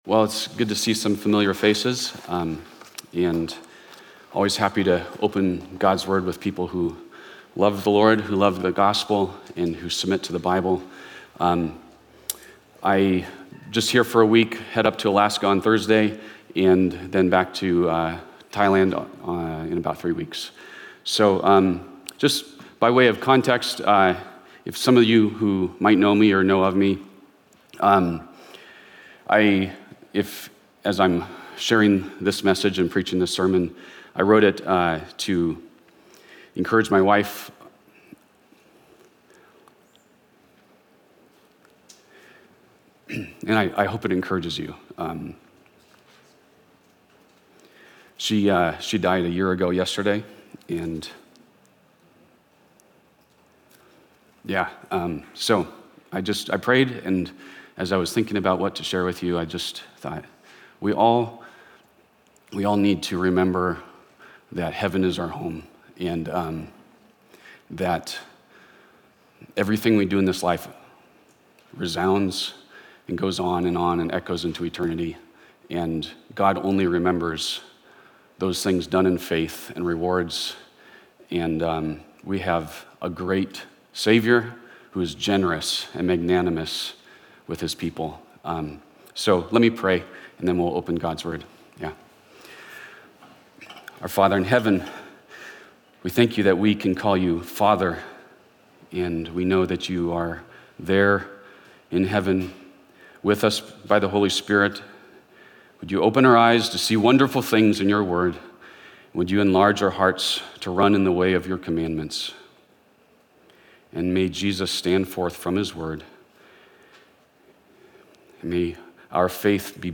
Secured for a Glorious Salvation (1 Kings 19:1-18) - Guest Speaker